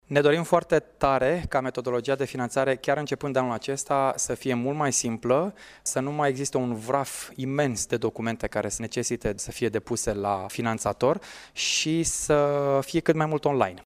Referindu-se la metodologia de finanţare Andrei Popescu a precizat că aceasta va fi simplificată şi va fi şi online: